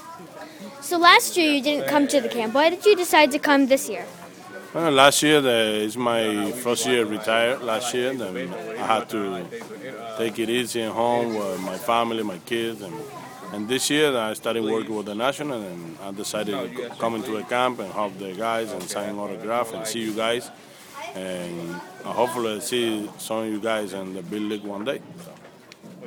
Since Livan has started to work with the Nats this year, he decided to come out and see camp this year.